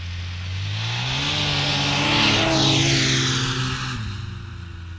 Subjective Noise Event Audio File (WAV)